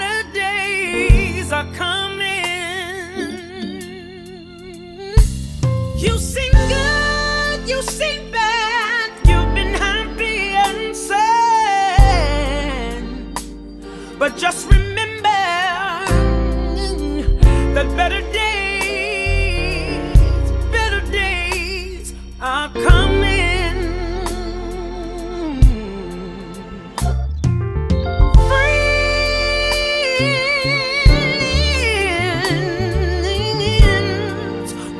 # Christian & Gospel